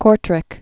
(kôrtrĭk) also Cour·trai (kr-trā, kr-)